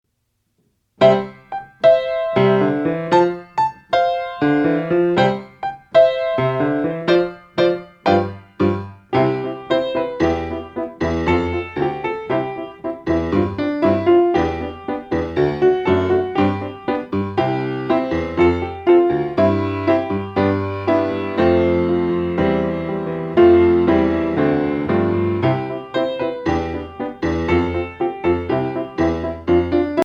*  Catchy melodies, dumb jokes, interesting stories